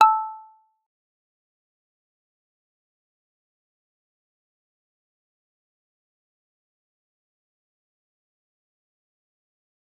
G_Kalimba-A5-f.wav